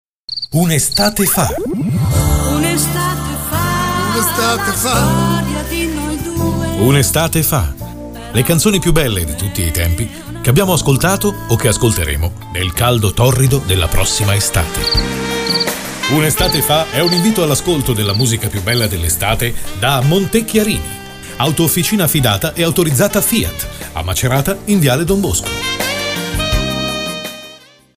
una voce maschile versatile: calda e profonda o dinamica e piena di energia.
Sprechprobe: eLearning (Muttersprache):
versatile Voice: warm and deep or dynamic and full of energy.